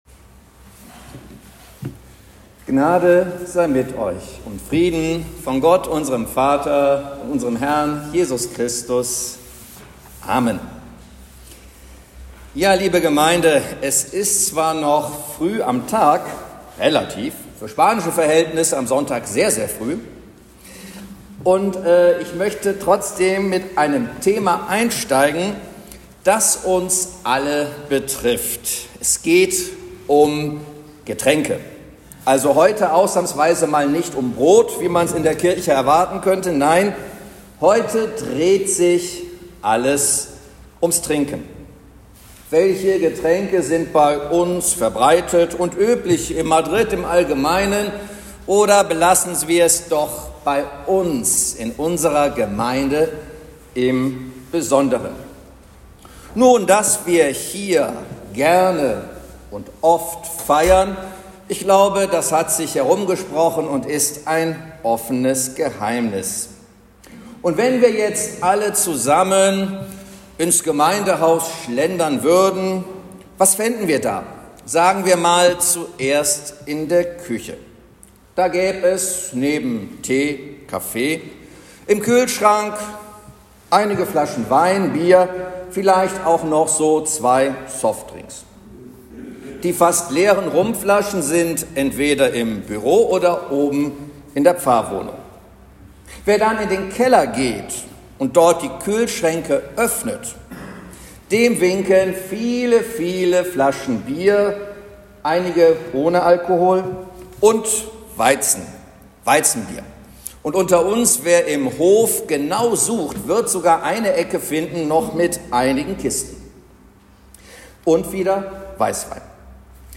Predigt zum 3. Sonntag nach Epiphanias vom 26.01.2025